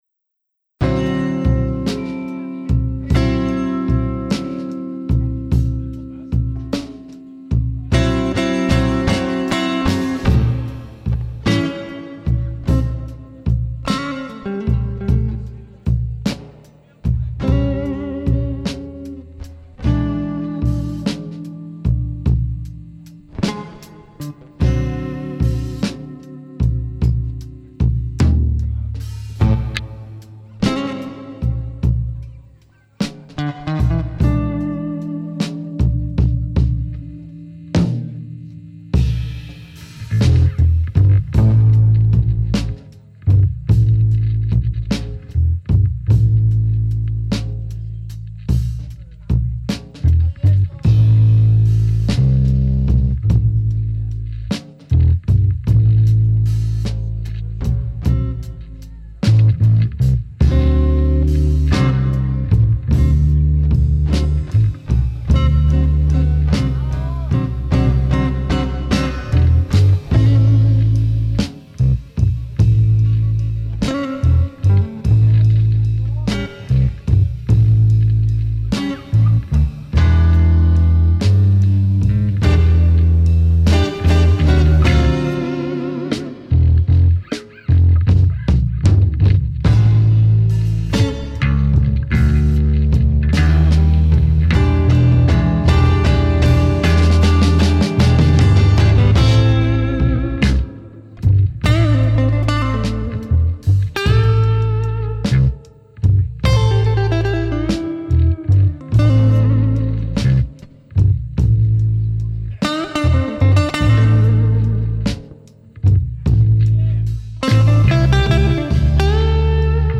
guitar
bass, vocals
drums